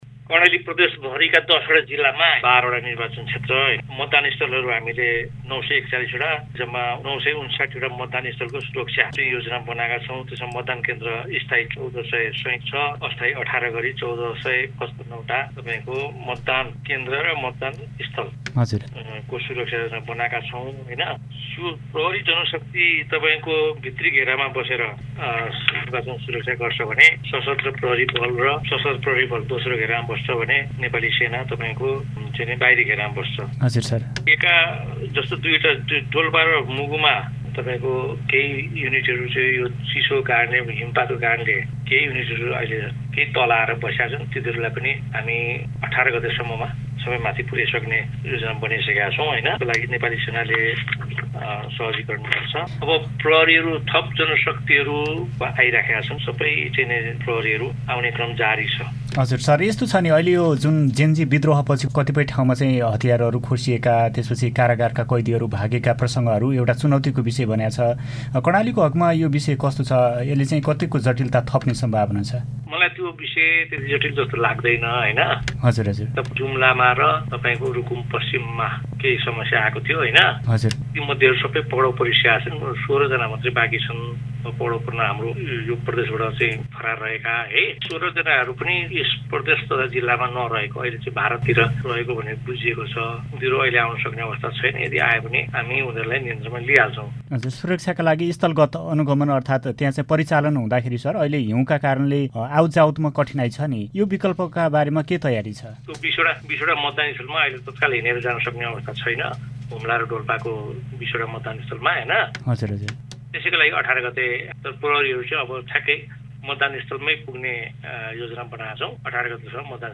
सुनौ यस बारे कर्णाली प्रदेश प्रहरी प्रमुख जयराज सापकोटासंगको यो कुराकानि
Interview-with-Jayaram-sapkota.mp3